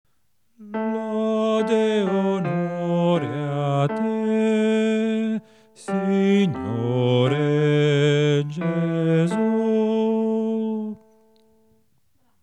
BASSI  –
CDV101-Lede-e-Onore-a-Te-Signore-Gesu-BASSI-Acclamazione-2^-melodia.mp3